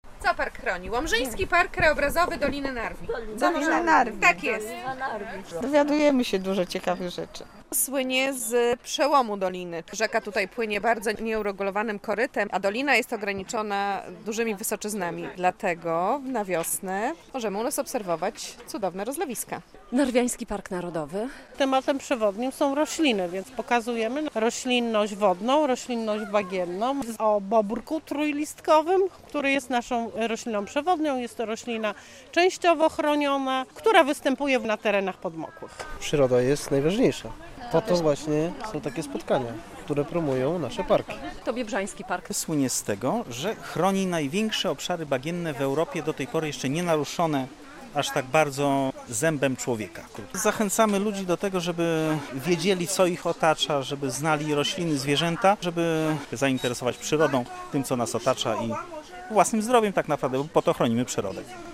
Piknik Podlaskich Parków Narodowych i Krajobrazowych - relacja